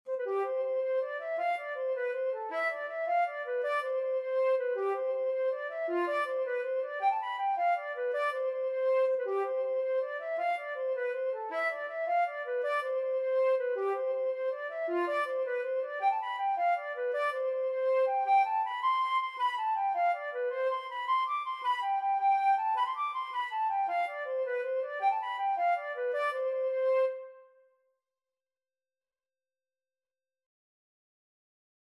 Flute version
6/8 (View more 6/8 Music)
Flute  (View more Easy Flute Music)
Traditional (View more Traditional Flute Music)